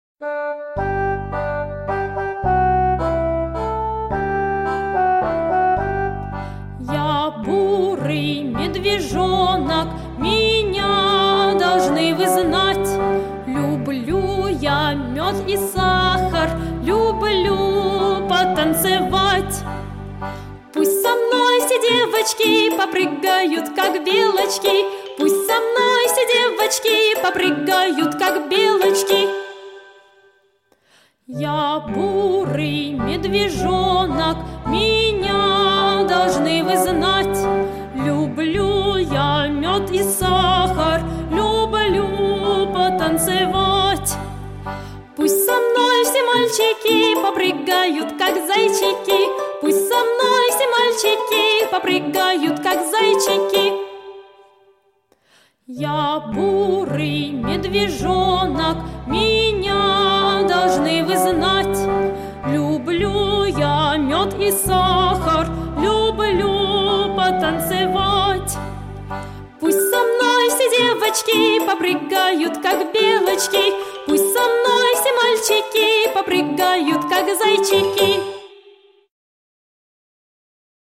детские песни и музыку